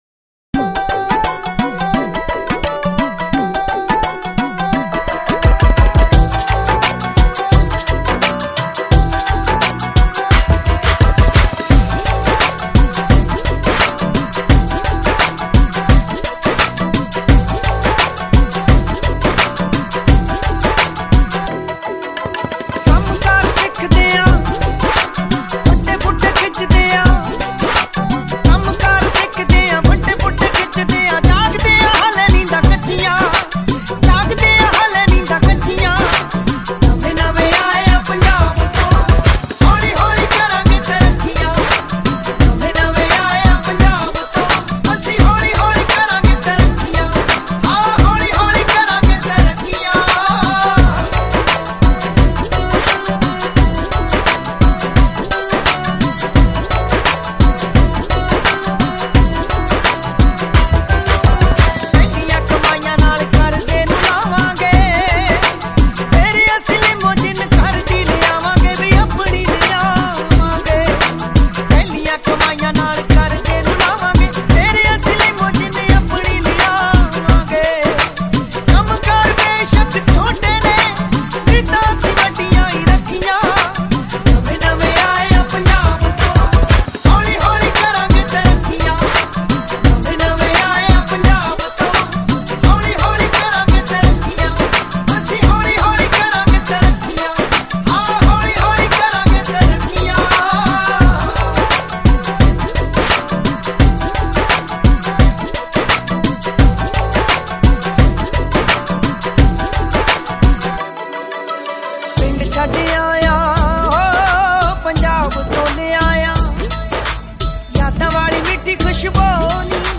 Category: UK Punjabi
Remix